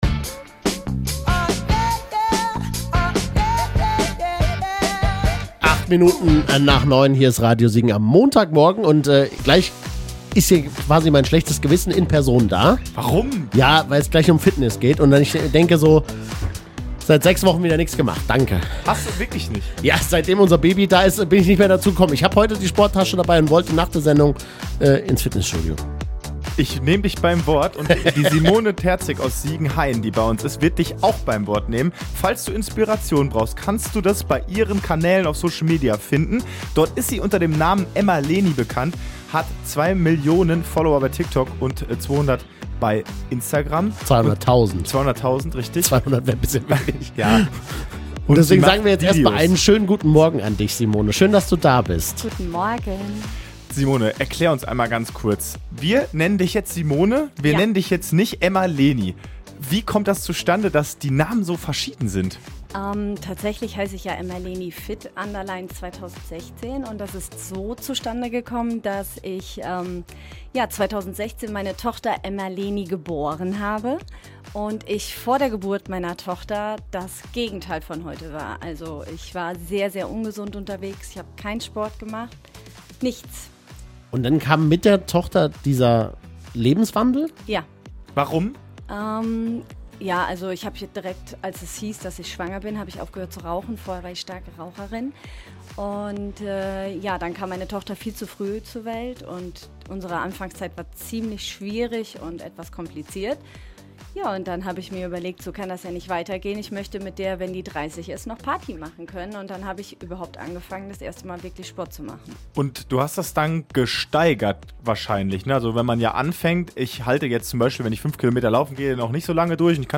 interview-teil-1.mp3